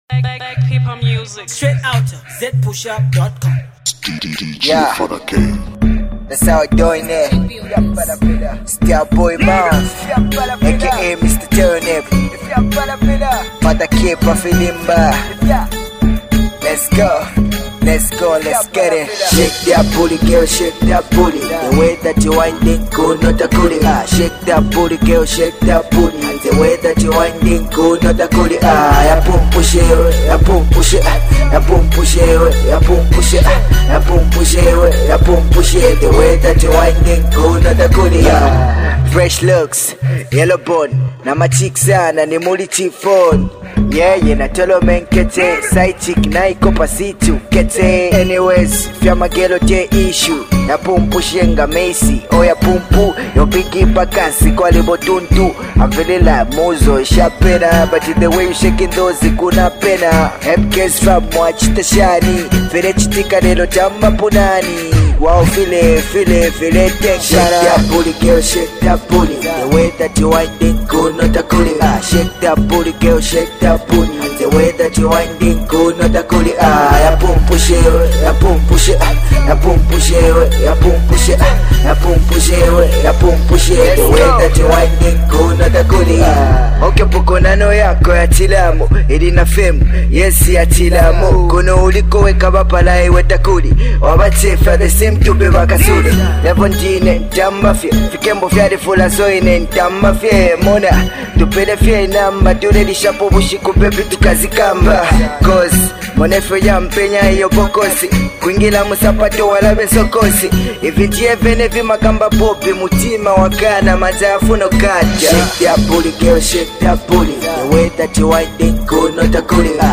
A talented young faster-rising rapper
Hip-hop/Dancehall